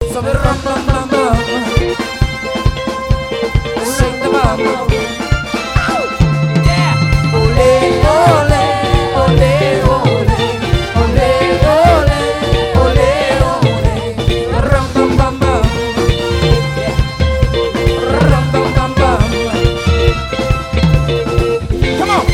PLAYING LIVE!!!!